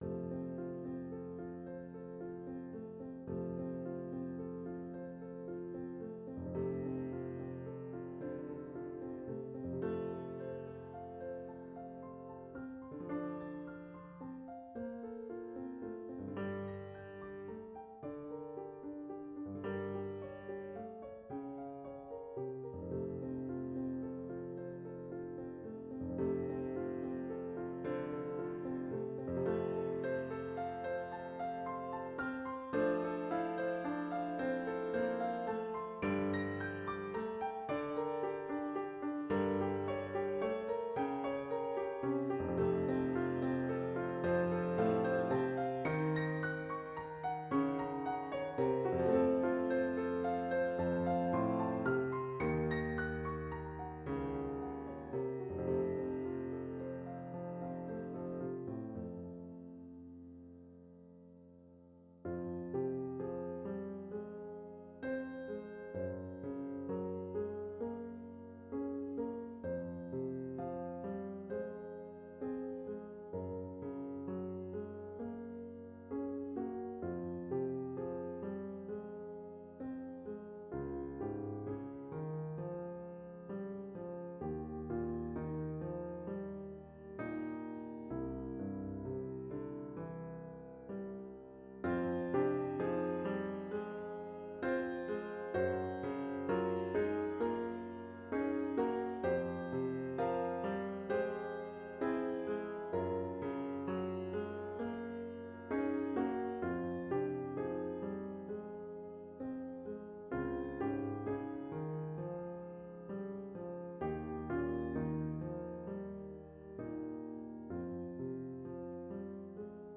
THE MUSIC: This Psalm Offering is in simple 4 part,AABA, form. Melody A is introduced in the left hand, while the right hand accompanies the melody with ascending and descending arpeggios and then restated. The melody segues into melody B, with arpeggios in the left hand and melody in the right. It segues back into melody A for the grand finale.